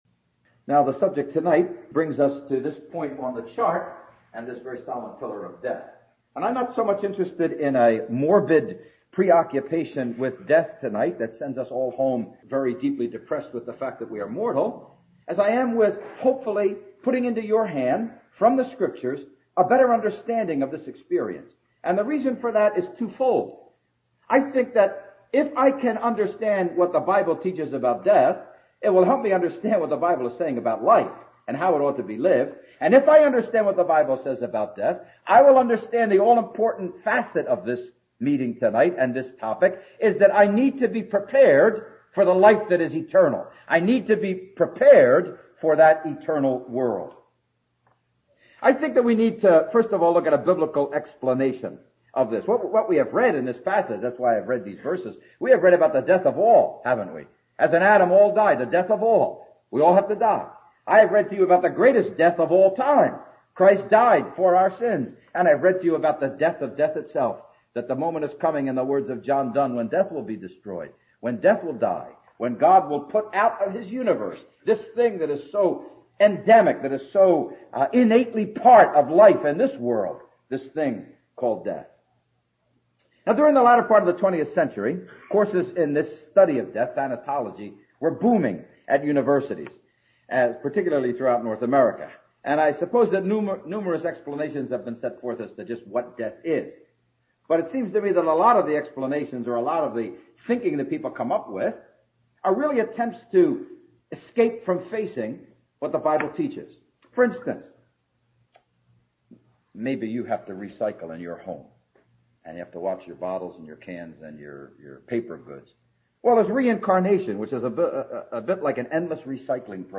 Under this question he deals with the difficult subject of death and delivers a challenging and thought-provoking message. (Message preached 1st August 2006)